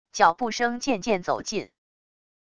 脚步声渐渐走近wav音频